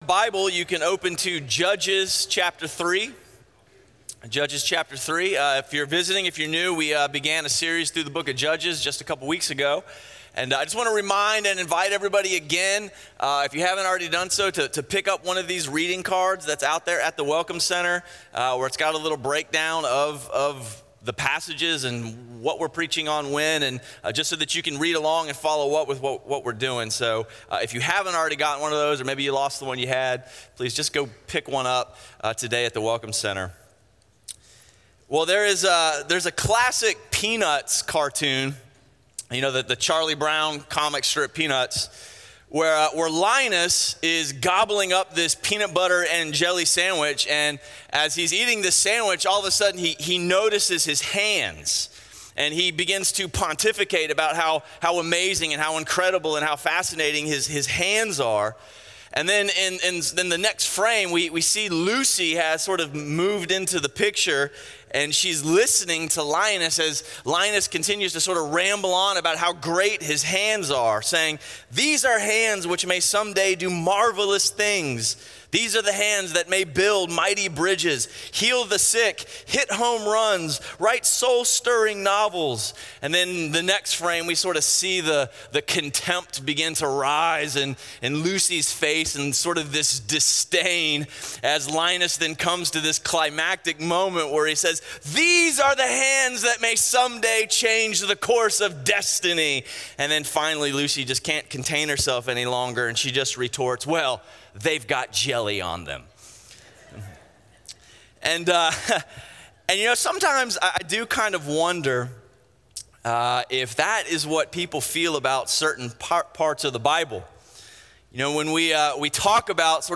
Message